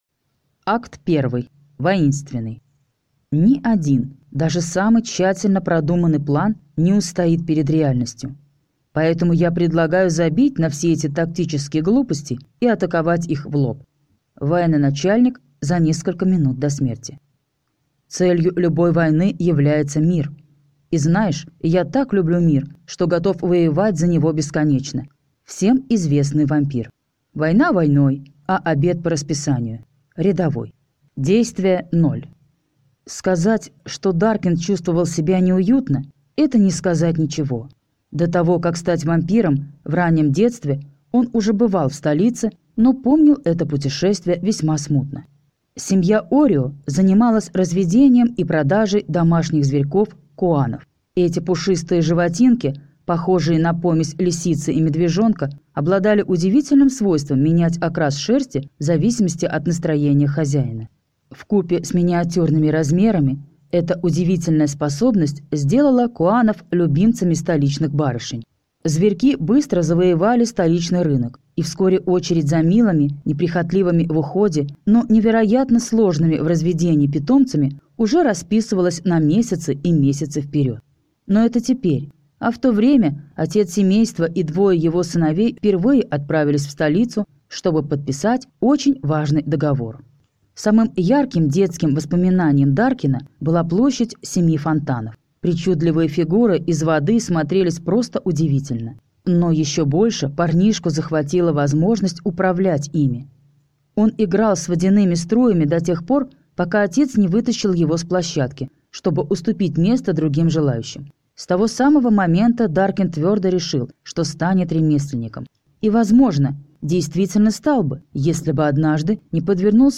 Аудиокнига Огненный Легион | Библиотека аудиокниг